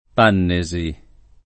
[ p # nne @ i ]